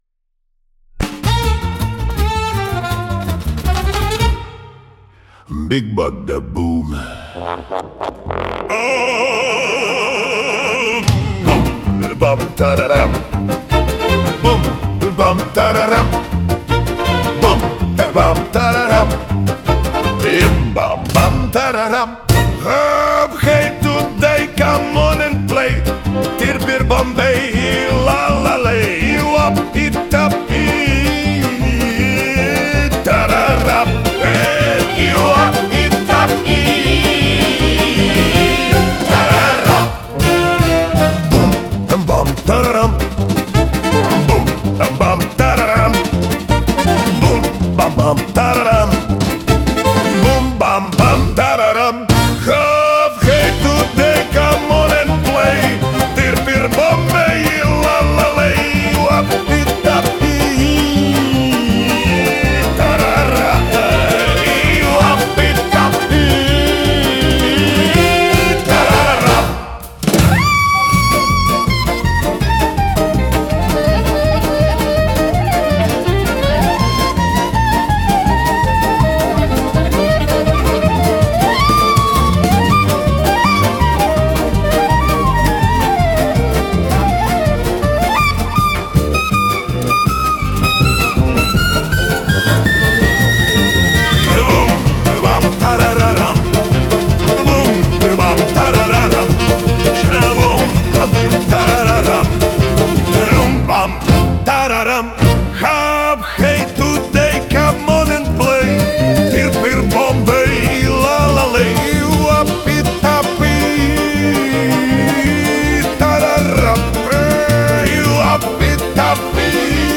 Джаз (657)